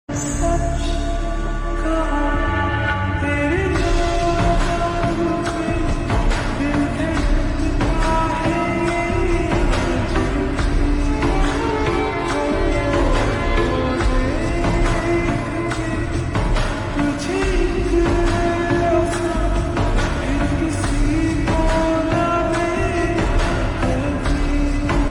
Slow Reverb Version
• Simple and Lofi sound
• Crisp and clear sound